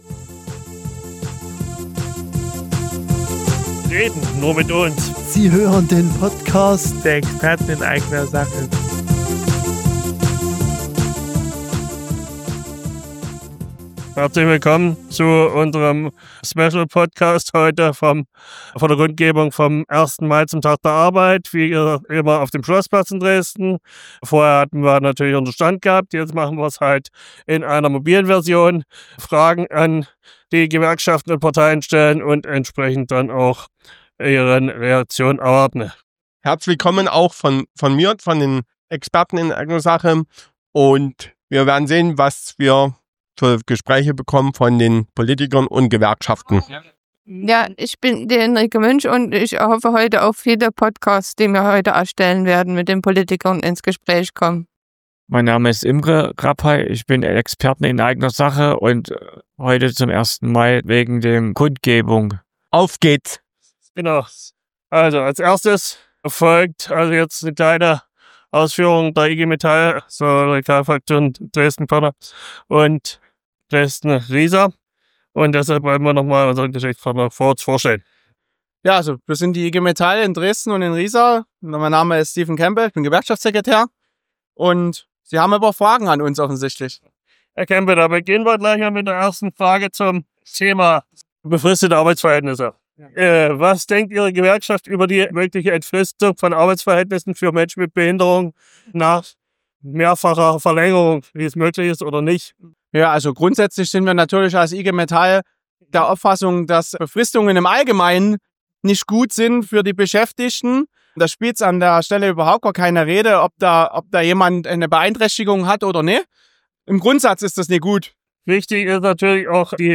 Am 1. Mai waren wir auf dem Schlossplatz in Dresden. Dort war die Kundgebung der Gewerkschaften zum Tag der Arbeit.
Unser Thema war: Inklusion von Menschen mit Behinderung im Arbeitsleben. Wir haben vier Gespräche geführt.